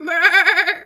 sheep_2_baa_11.wav